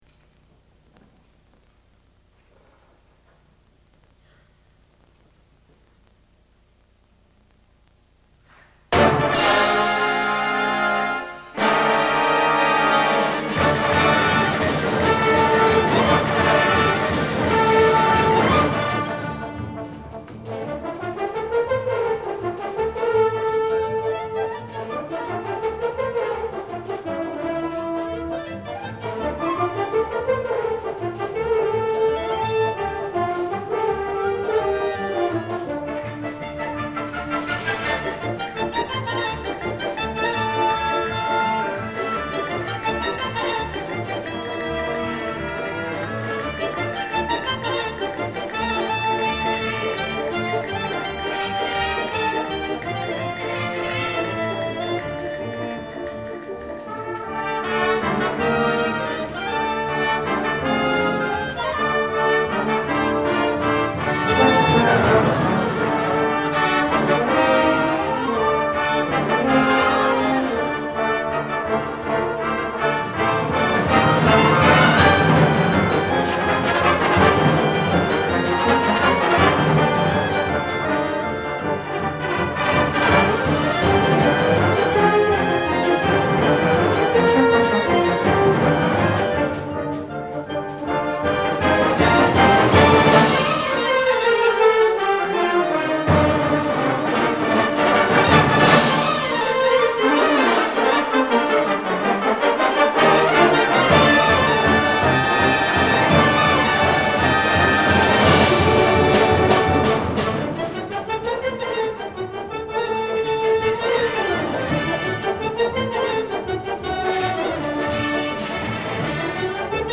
第46回 神奈川県吹奏楽コンクール